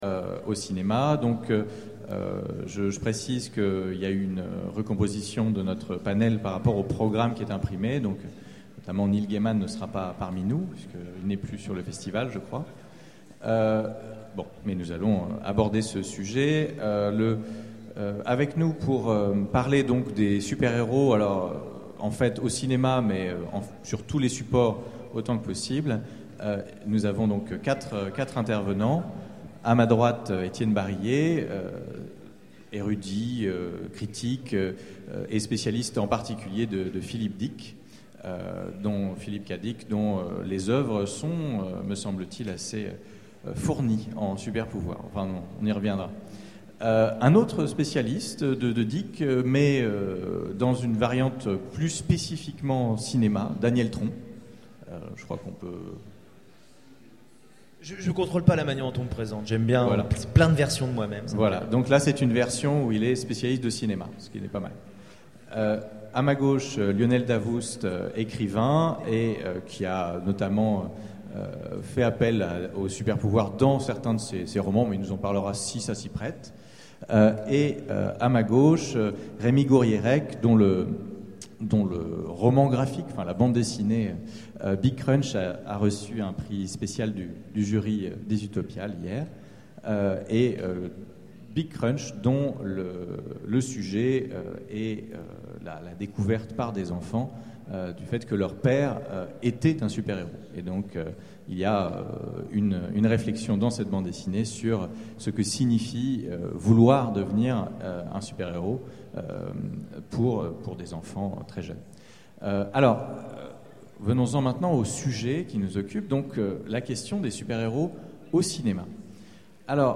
Utopiales 12 : Conférence Le retour des super héros, des comics au cinéma